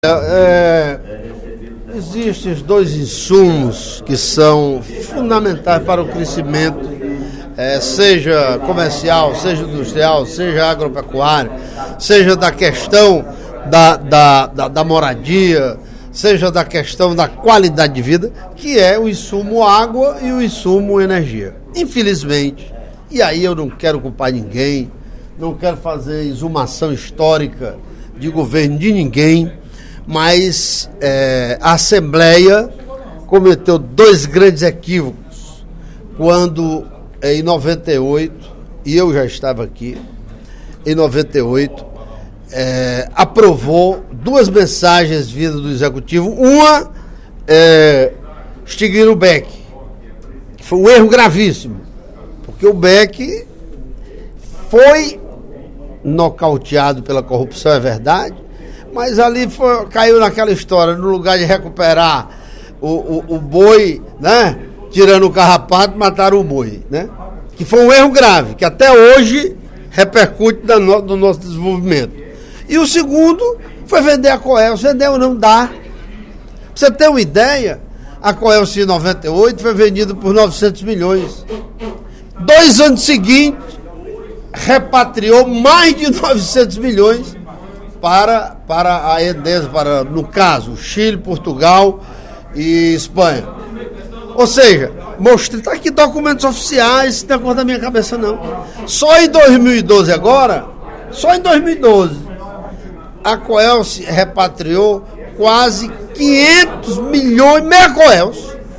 Em pronunciamento durante o primeiro expediente da sessão plenária desta terça-feira (28/05), o deputado Carlomano Marques (PMDB) anunciou que irá apresentar três propostas de emenda à Constituição Estadual, que corrigem “conflitos existentes entre as constituições Federal e Estadual”.